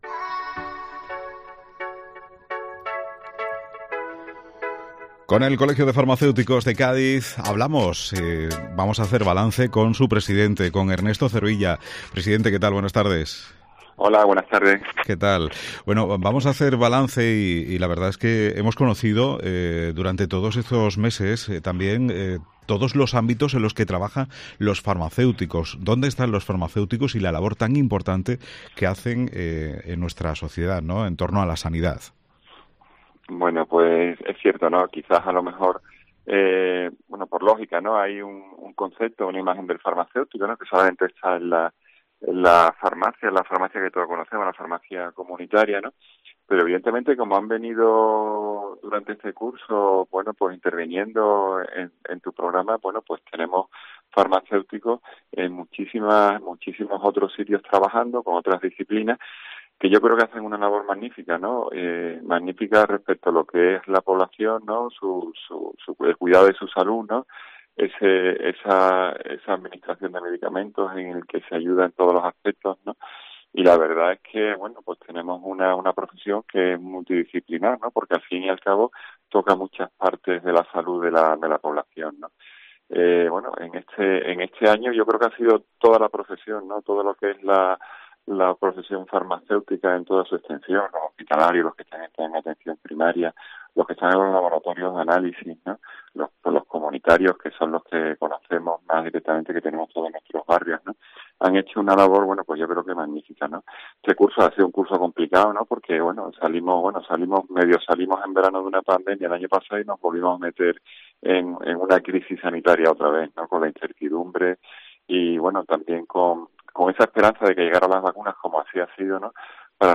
La labor en esa atención directa al ciudadano pero también en otras parcelas de esta "profesión multidisciplinar forman parte de las reflexiones presentes en esta entrevista en Mediodía COPE Provincia de Cádiz.